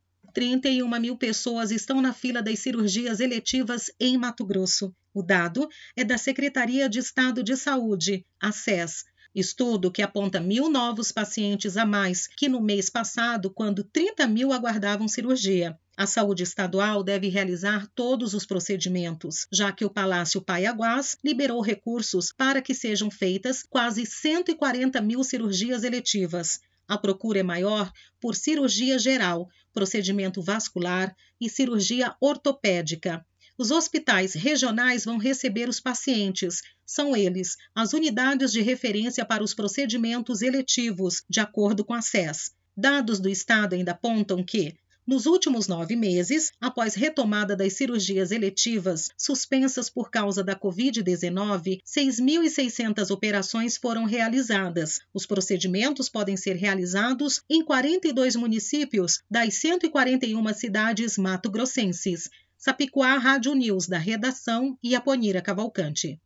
Boletins de MT 20 jun, 2022